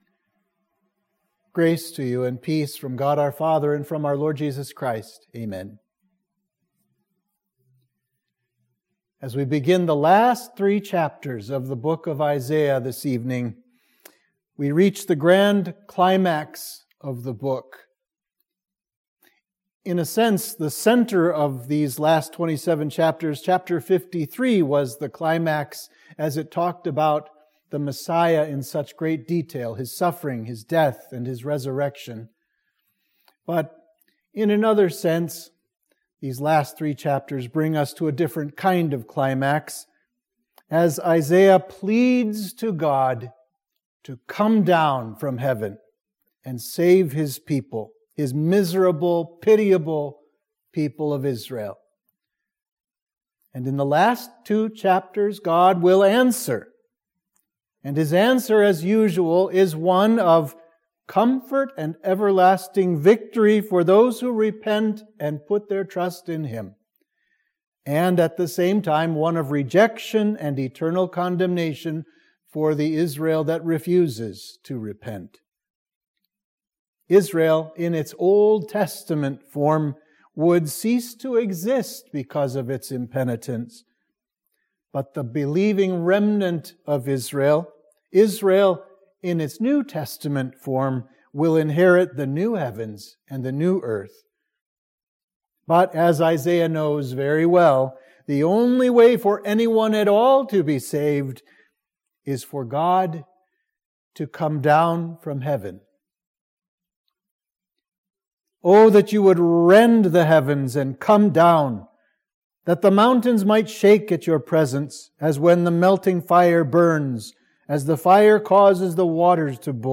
Sermon for Midweek of Trinity 25